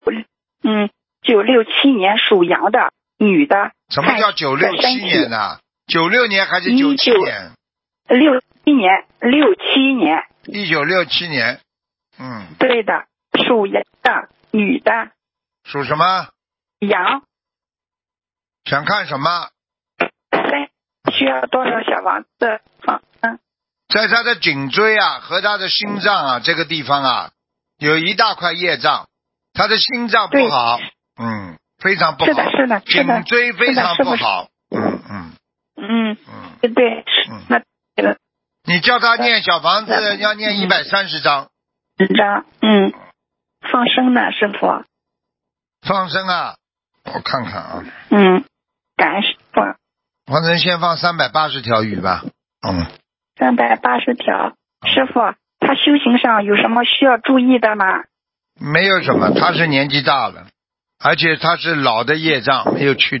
目录：2019年12月_剪辑电台节目录音_集锦